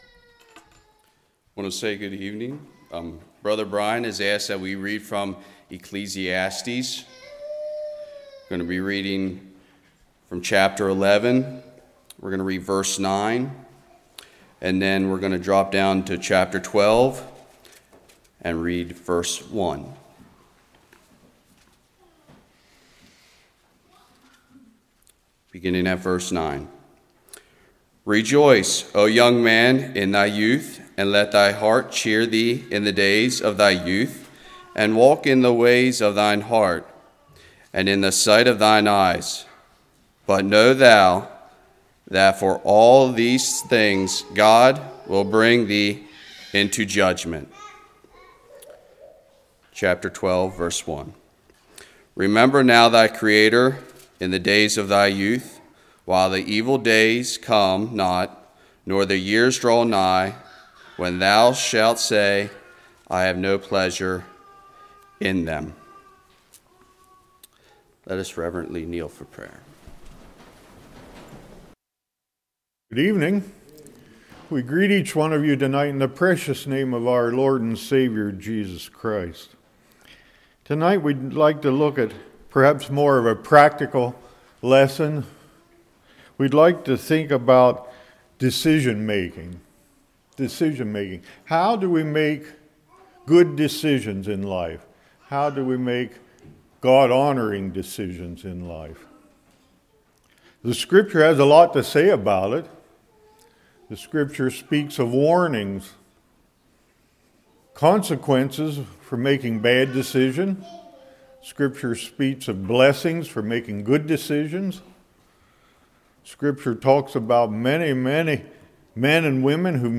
Ecclesiastes 11:9-12:1 Service Type: Evening Scripture warns us against making decisions based on impulse and desires.